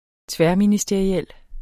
Udtale [ ˈtvεɐ̯minisdeɐ̯iˌεlˀ ]